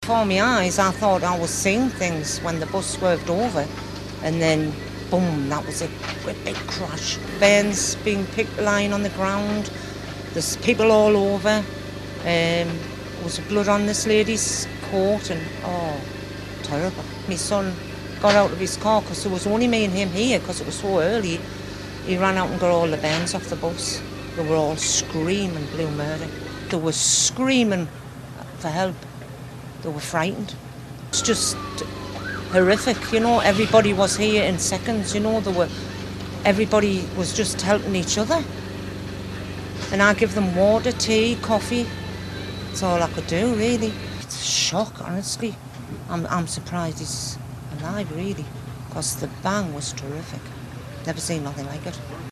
County Durham bus crash - Eye witness
We've spoken to a lady who saw two buses collide in Stanley, County Durham this morning which has left two people "seriously injured" in hospital with more than 20 children taken to hospital.